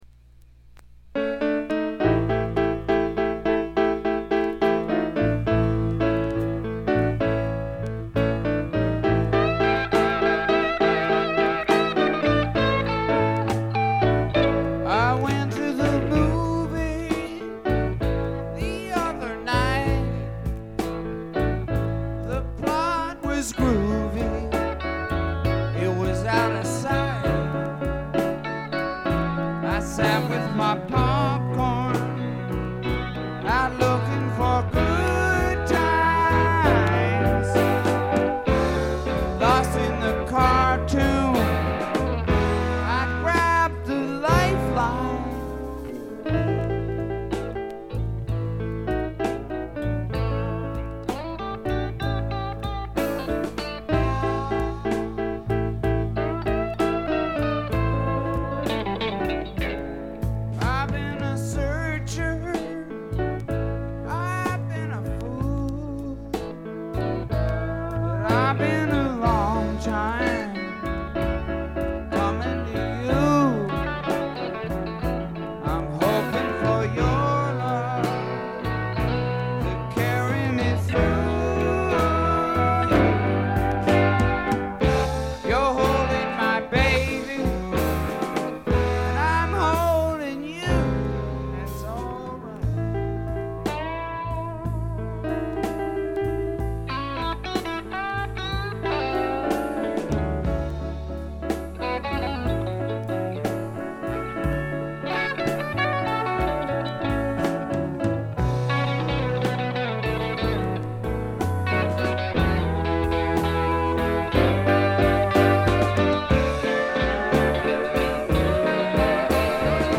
特に目立つノイズはありません。
試聴曲は現品からの取り込み音源です。
piano
steel guitar
bass